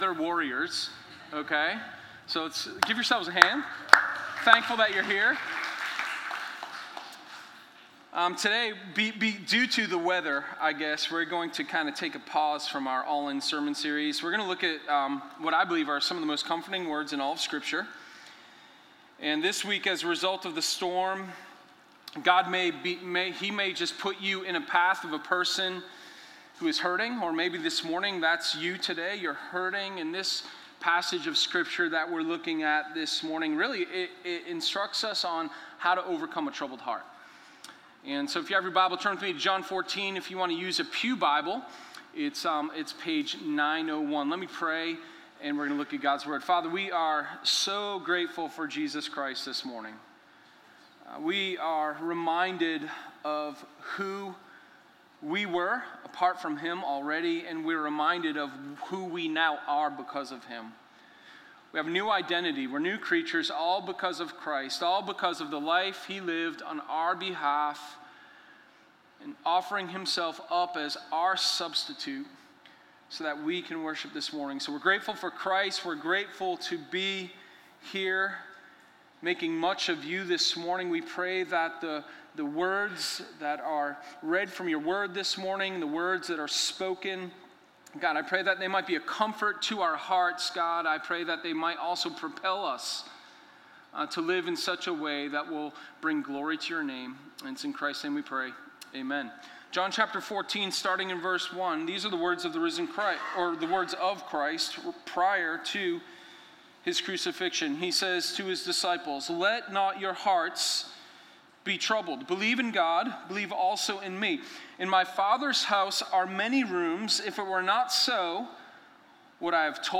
Sermon0916_HopeforaTroubledHeart.mp3